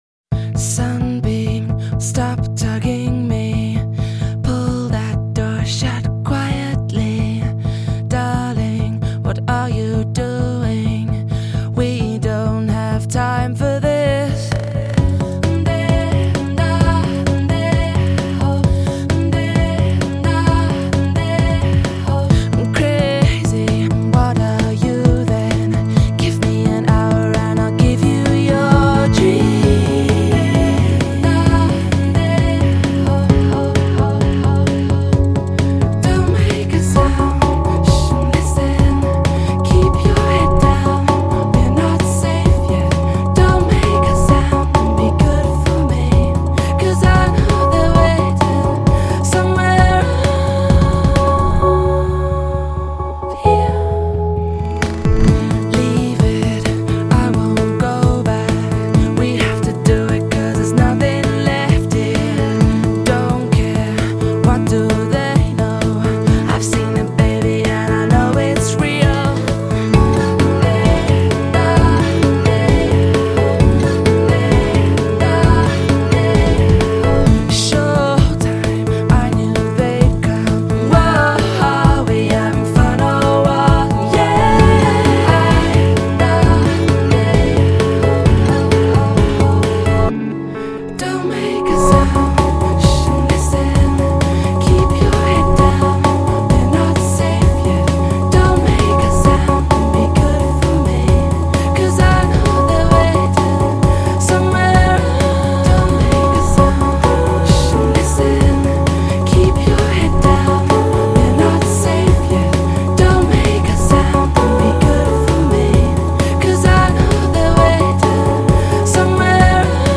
موسیقی الکترونیک موسیقی الکتروپاپ